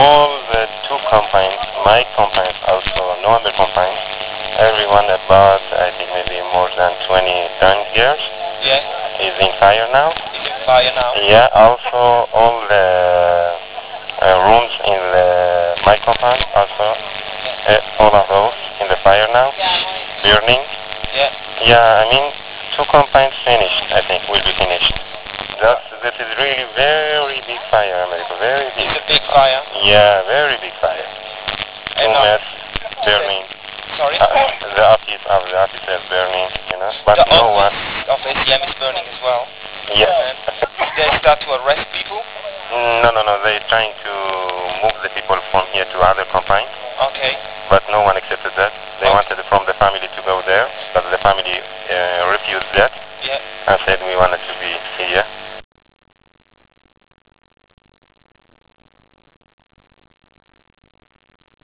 A SHORT AUDIO INTERVIEW WITH A DETAINEE RECORDED DURING THE FIRE IS
(sorry for the poor quality)